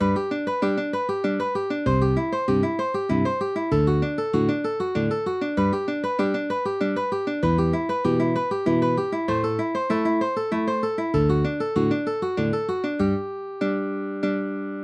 Enchainement d'accords deuxième exercice
Avec la main droite en accord, on peut faire des pompes : première note puis les deux autres simultanément, les trois ensemble au rytme de la main gauche (1 par temps) ....
Enfin en mettant le turbo en doublant tout sur chaque mesure :